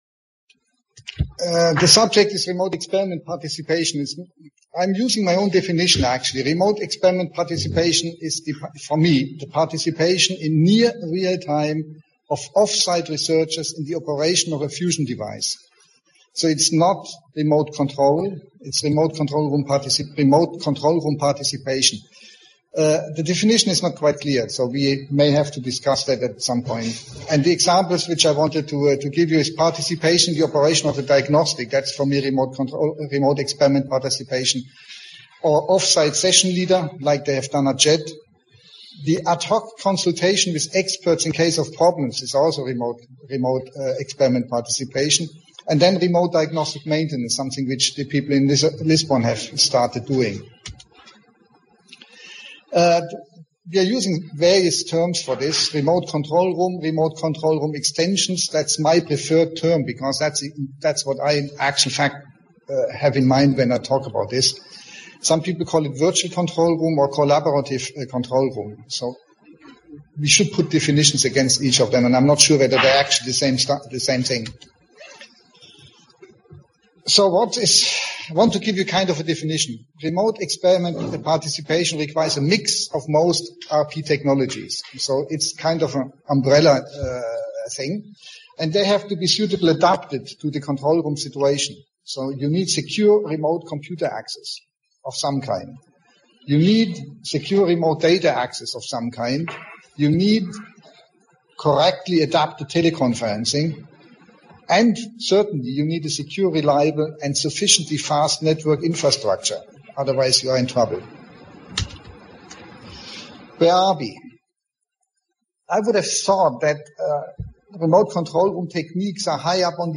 EFDA- Remote Participation Contacts Workshop, Riga, Latvia